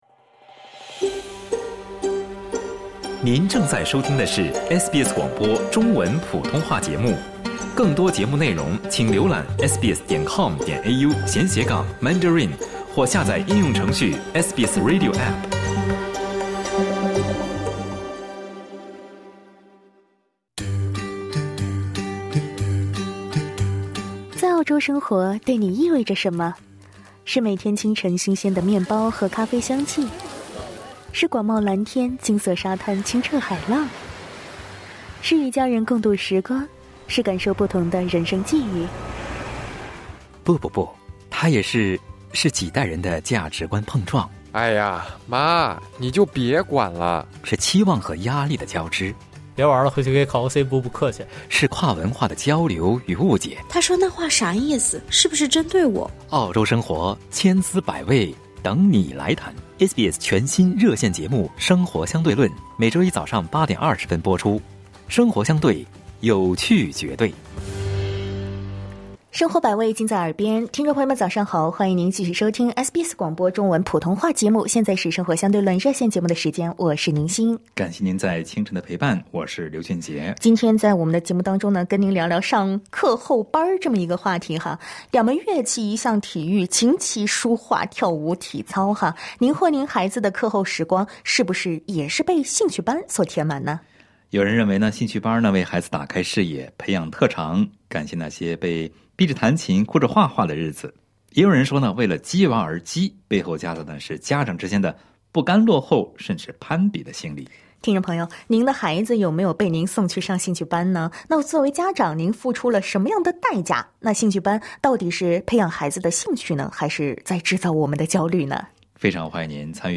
《生活相对论》热线节目 每周一早晨8:30在SBS普通话电台播出。